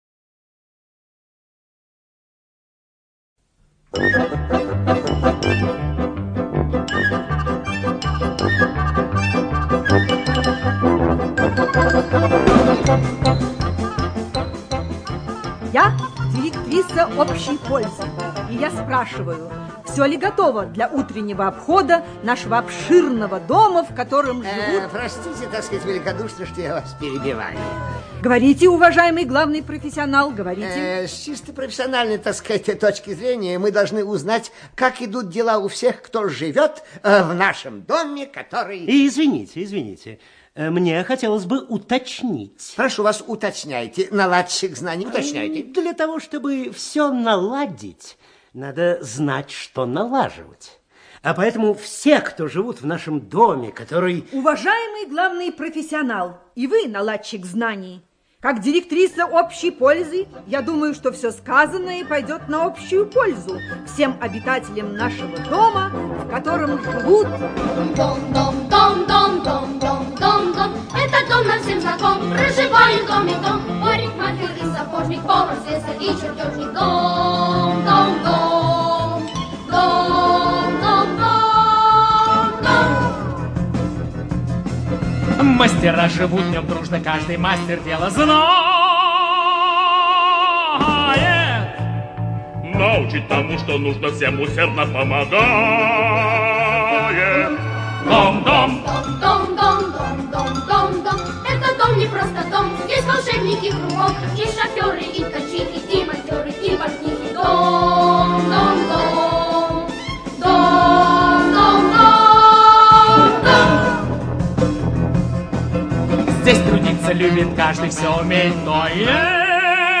ЖанрМузыкальный радиоспектакль